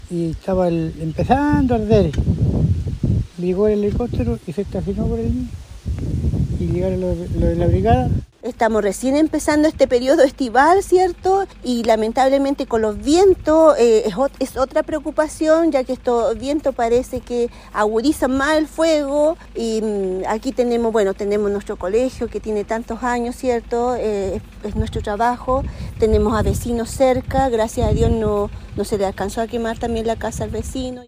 Quienes viven en el sector estuvieron alertas ante el rápido avance de las llamas, según relataron a Radio Bío Bío.